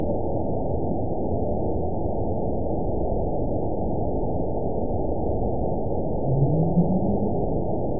event 920400 date 03/23/24 time 08:04:53 GMT (1 year, 1 month ago) score 8.88 location TSS-AB02 detected by nrw target species NRW annotations +NRW Spectrogram: Frequency (kHz) vs. Time (s) audio not available .wav